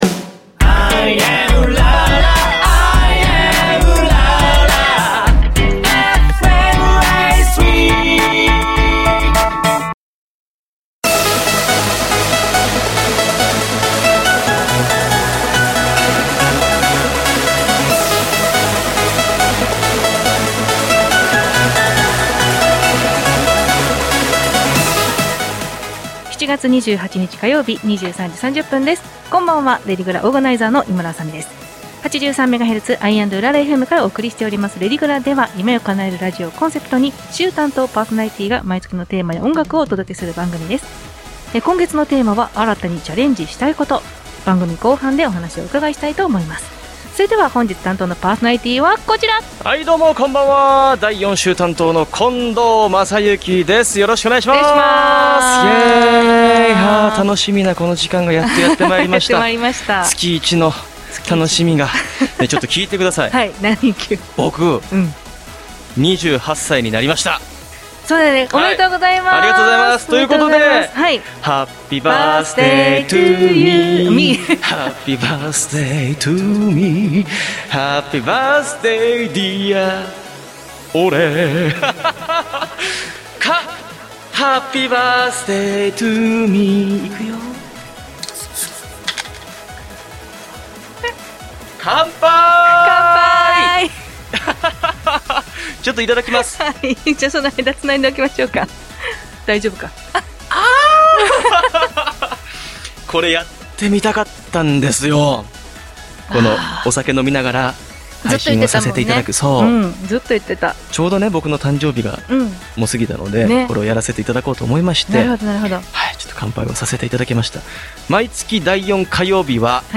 『夢を叶えるラジオ』をコンセプトに、夢を叶えるため頑張るお話や、毎月変わるテーマについて、週替わりパーソナリティーやゲストと共に、お便りやメッセージなどをお届けする番組。また、番組の冒頭と最後に毎月全４週で完結するラジオドラマを放送。エンディング曲は毎月変わります。